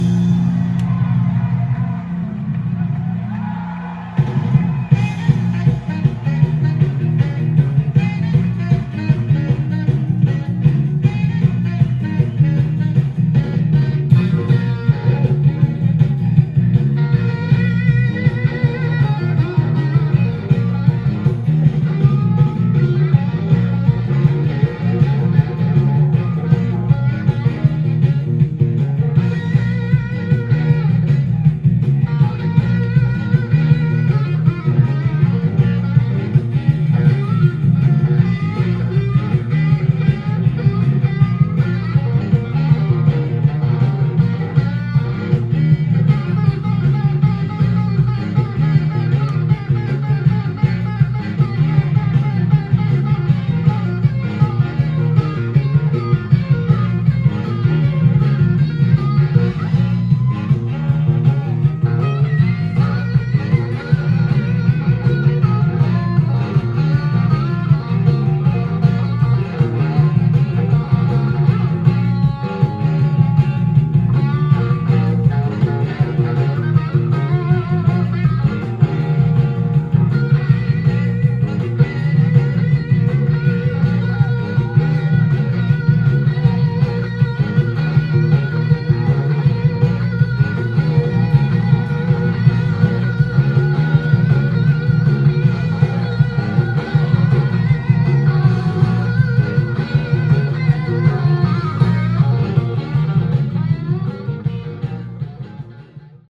店頭で録音した音源の為、多少の外部音や音質の悪さはございますが、サンプルとしてご視聴ください。